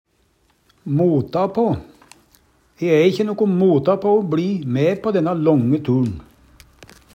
mota på - Numedalsmål (en-US)